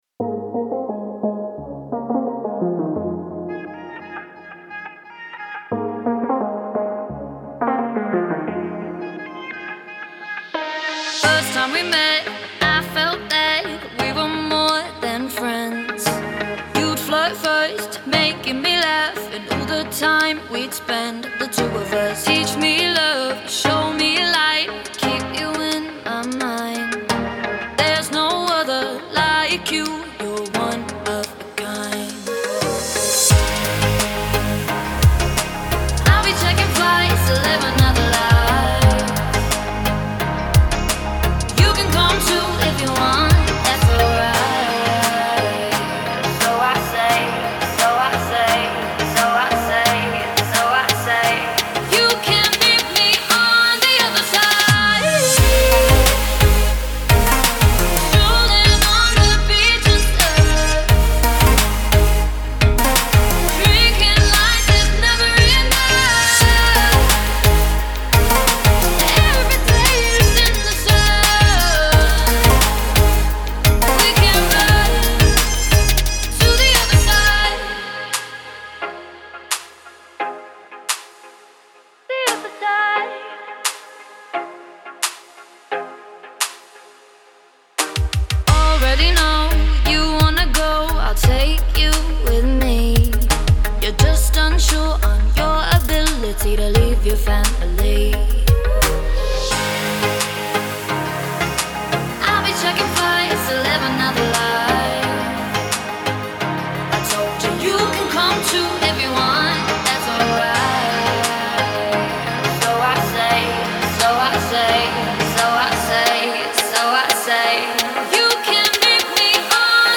это яркая и мелодичная композиция в жанре поп